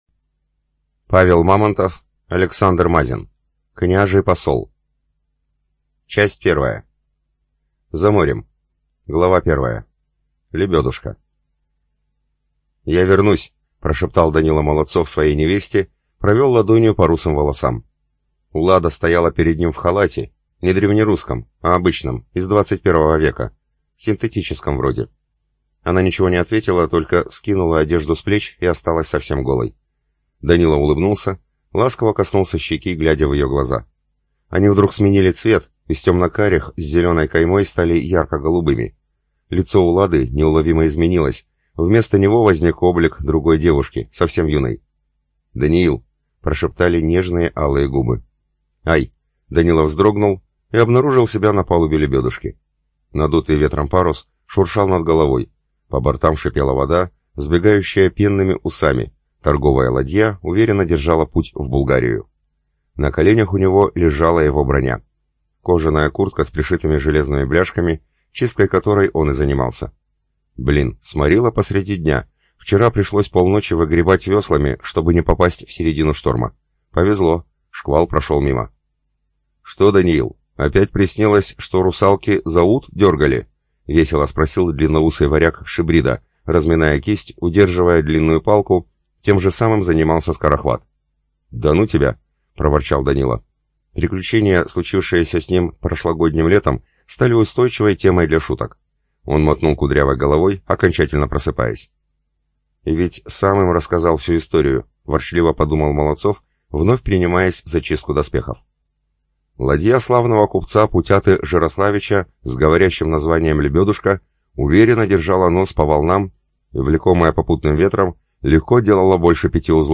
ЖанрФантастика, Историческая проза, Боевики, Фэнтези